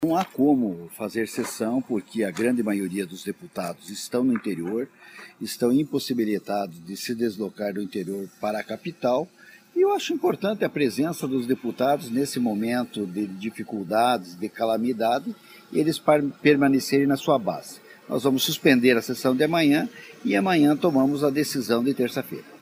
Confira a sonora do presidente do Legislativo, deputado Valdir Rossoni (PSDB), sobre a decisão de suspender a sessão plenária de segunda-feira (9).